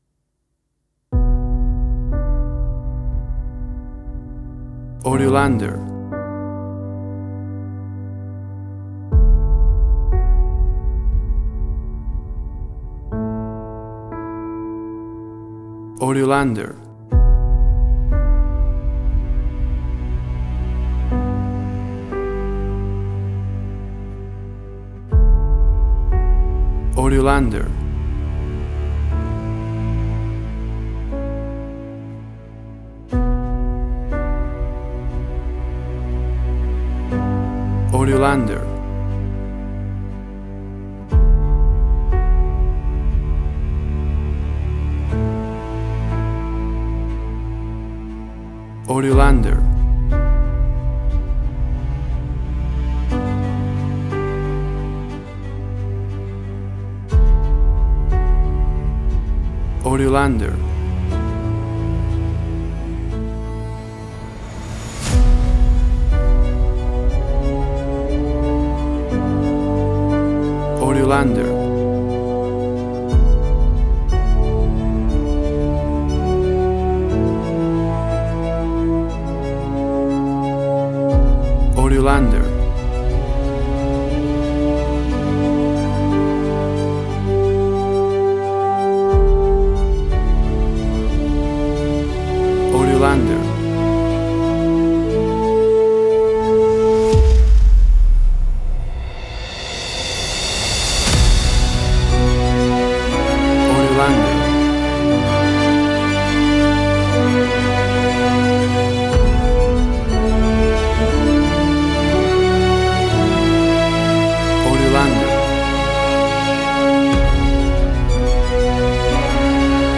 Tempo (BPM): 60